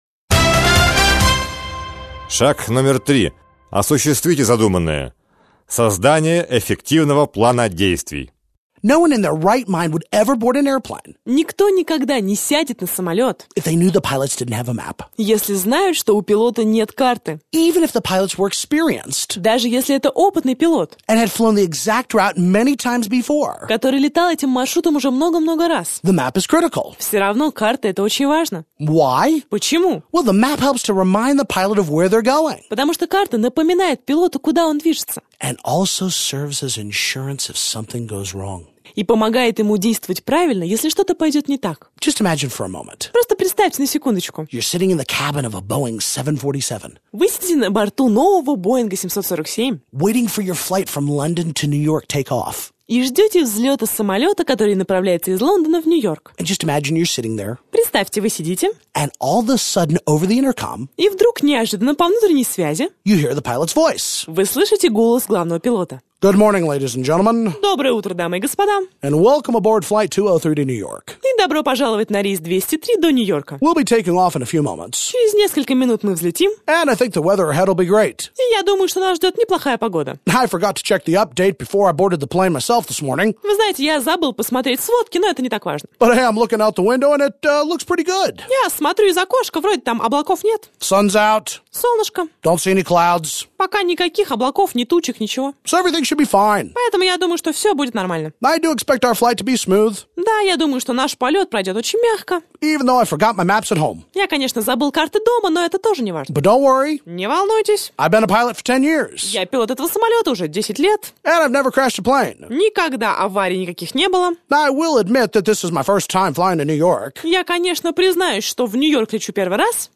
Джон Вон Эйкен Аудиокнига